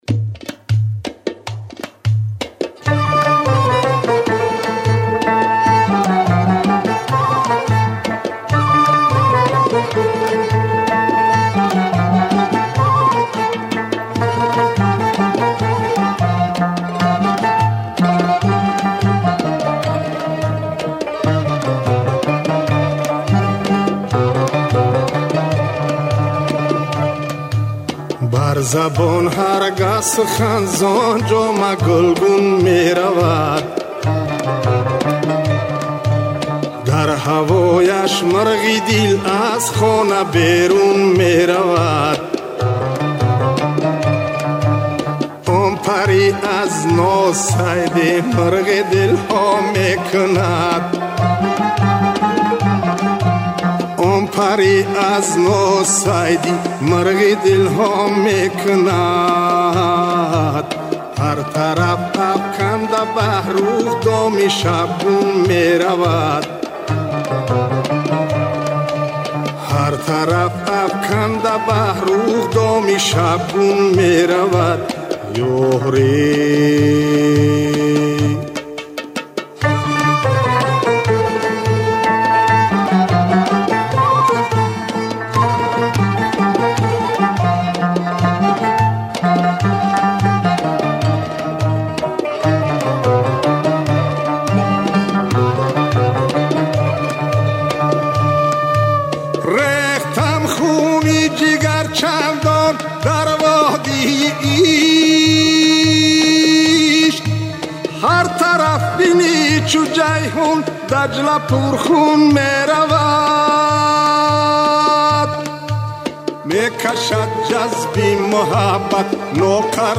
Мурғи дилро бо садои хонандаи тоҷик бишунавед: